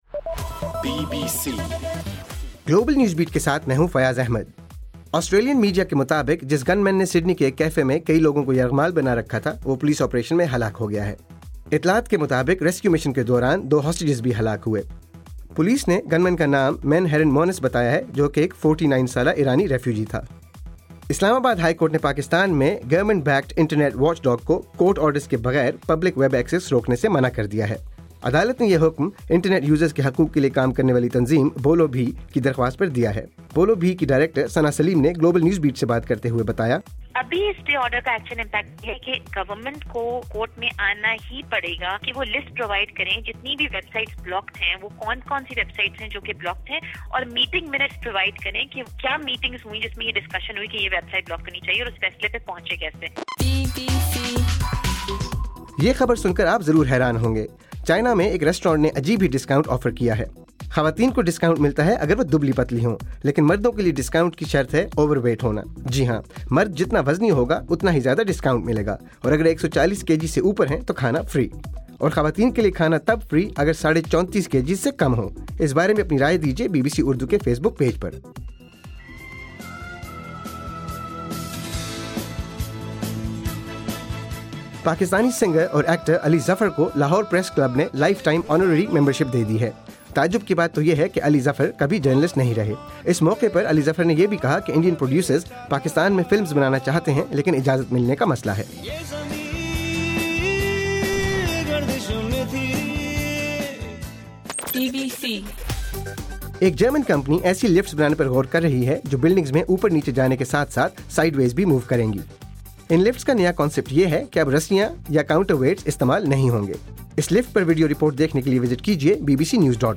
دسمبر 16: صبح 1 بجے کا گلوبل نیوز بیٹ بُلیٹن